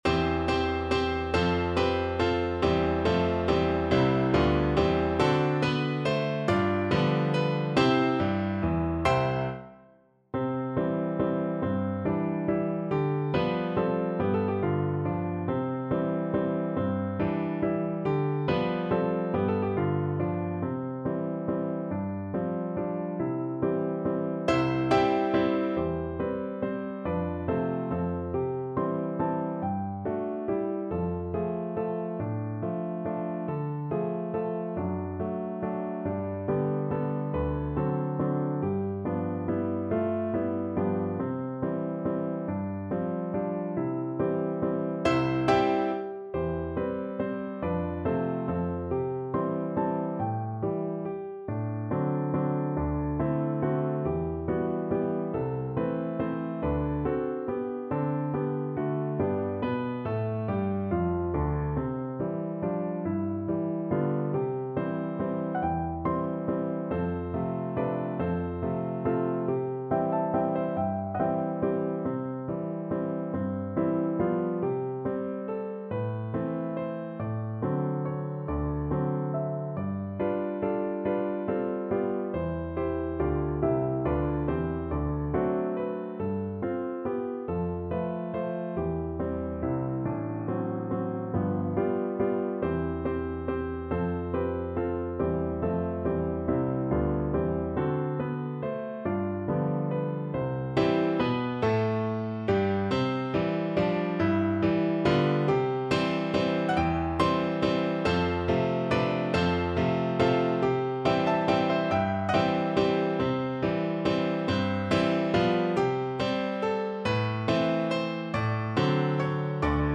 3/4 (View more 3/4 Music)
~ = 140 Tempo di Valse